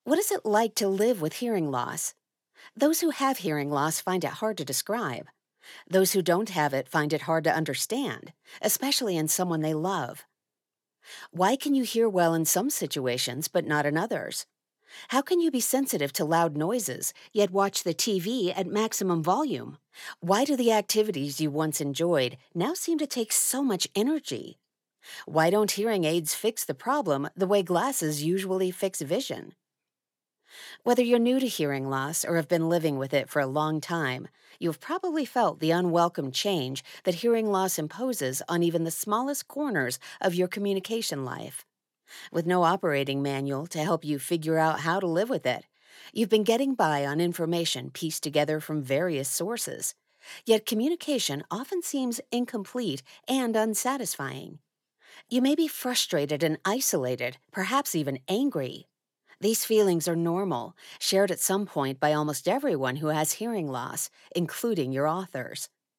Audiobook narrator
Nonfiction.mp3